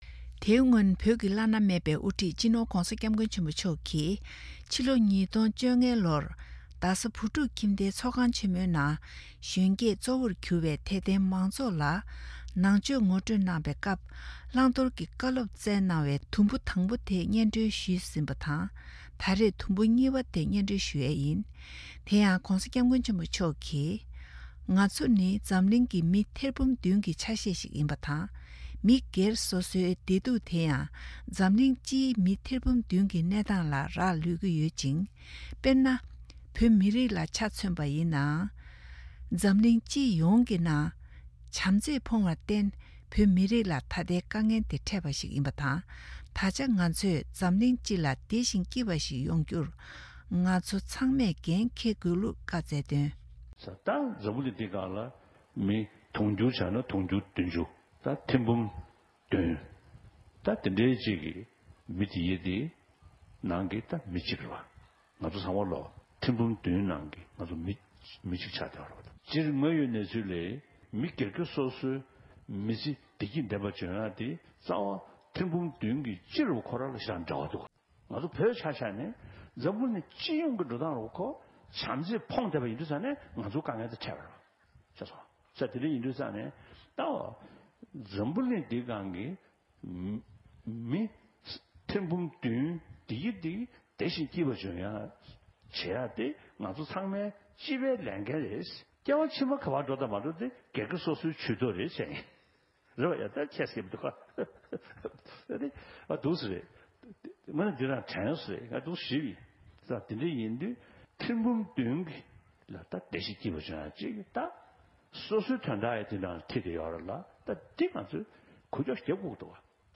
Dalai Lama Talks at TCV in 2015 Part Two